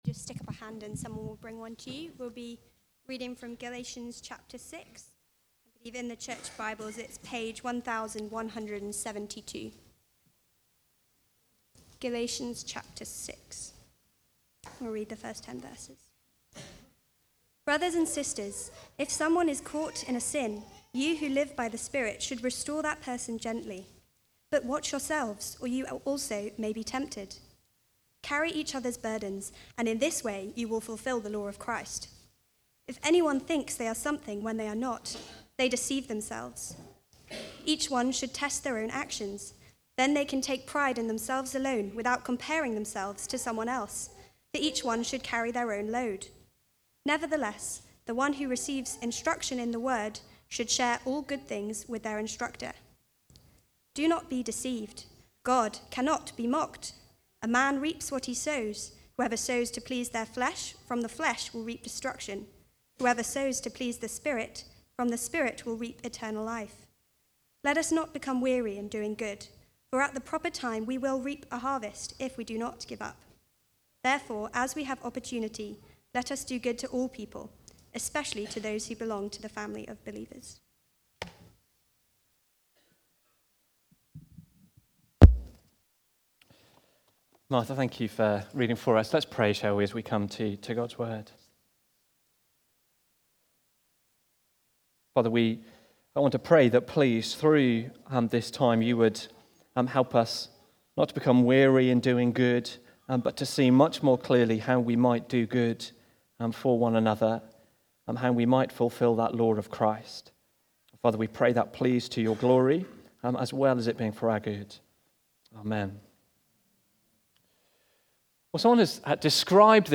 Preaching
The Gospel Community (Galatians 6:1-10) from the series Galatians - the Glorious Gospel. Recorded at Woodstock Road Baptist Church on 10 November 2024.